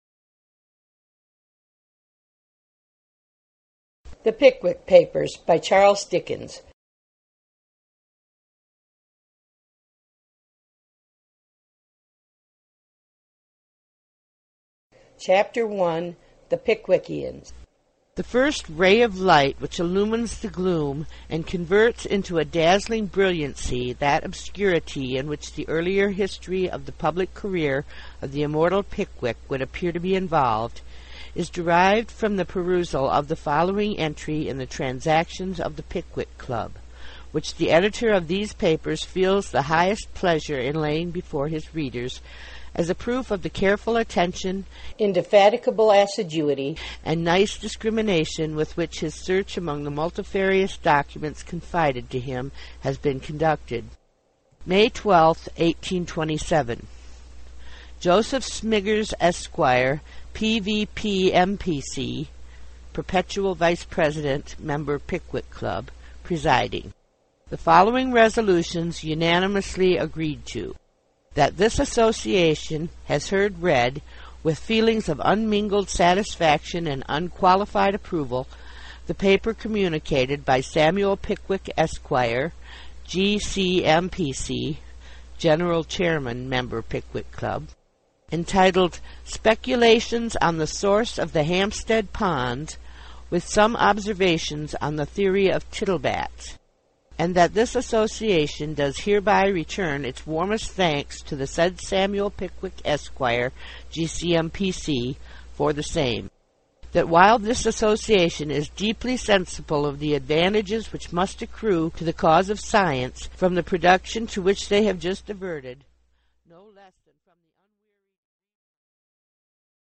Audiobook - Charles Dickens